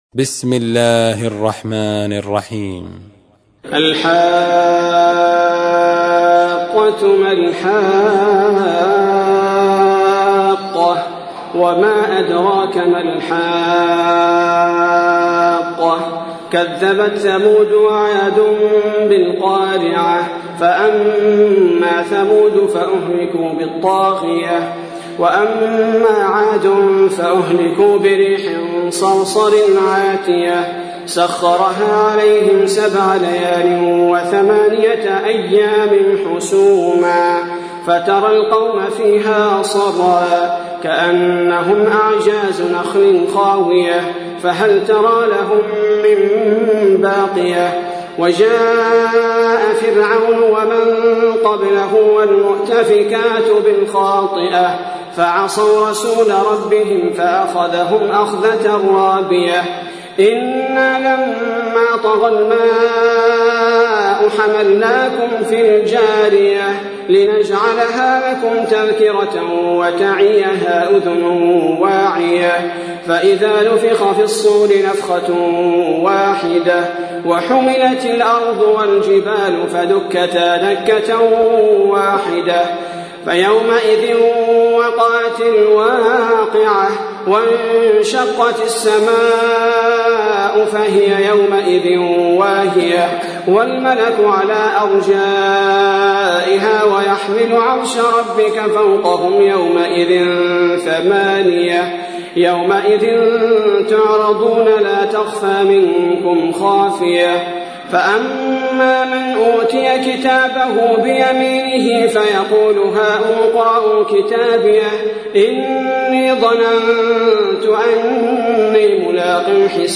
تحميل : 69. سورة الحاقة / القارئ عبد البارئ الثبيتي / القرآن الكريم / موقع يا حسين